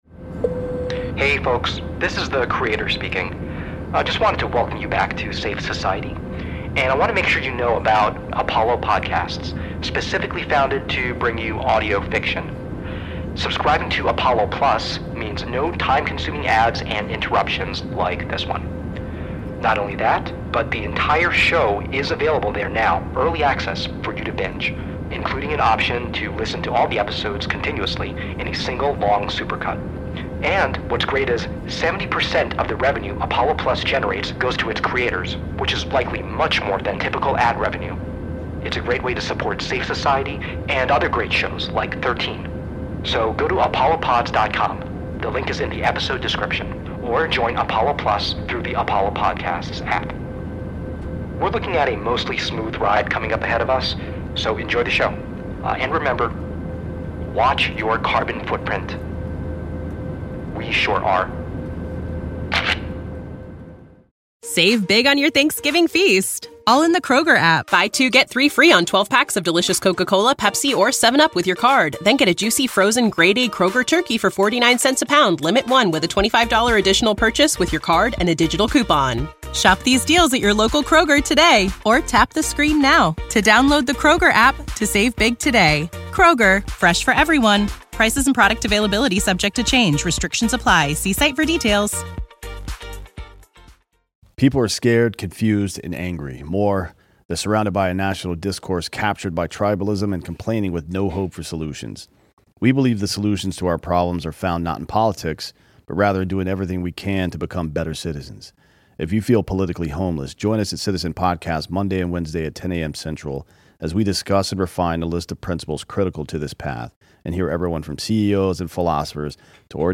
CONTENT WARNINGS Moderate language, moments of unsettling and sudden loud sound design, a. Podcast links by Plink.